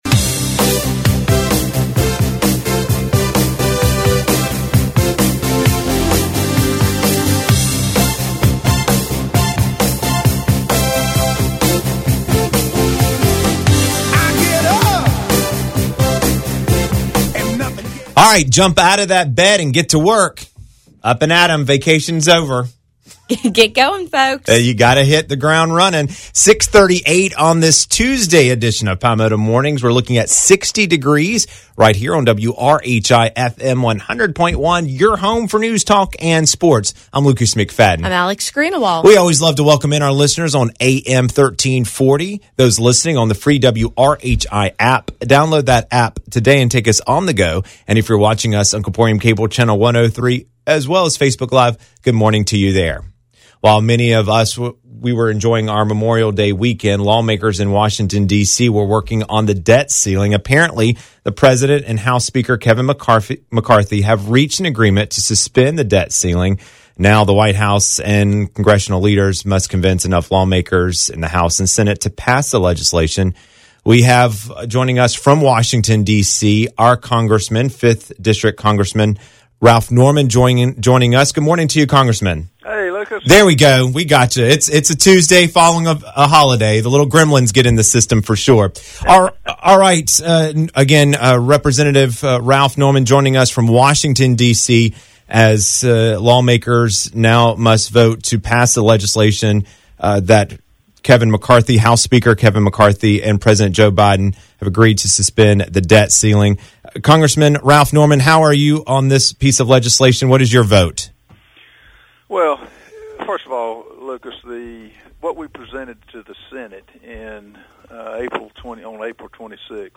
5th District U.S. Congressman Ralph Norman calls in to Palmetto Mornings.